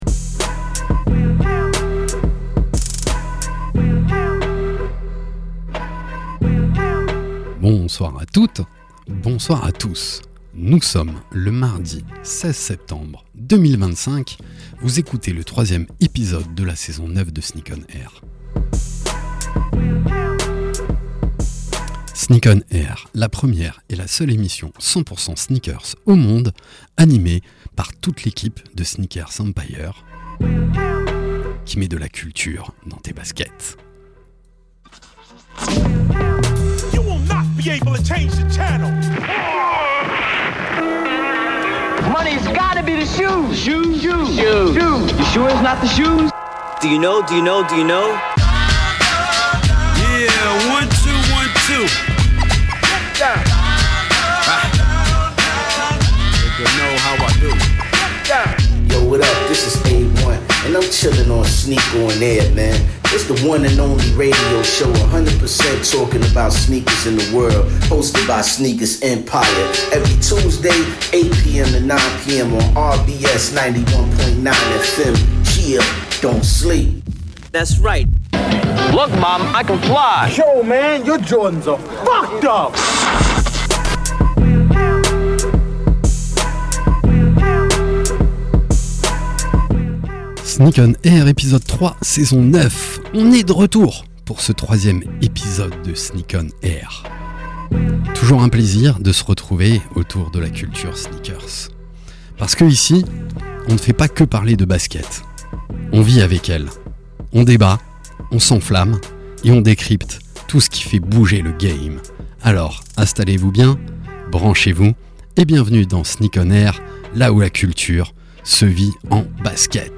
Sneak ON AIR, la première et la seule émission de radio 100% sneakers au monde !!! sur la radio RBS tous les mardis de 20h à 21h.
Pour cet épisode, nous vous proposons une heure de talk, d’actus, et de débats autour des faits marquants de l’univers de la sneaker avec tous nos chroniqueurs.